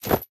equip_chain1.ogg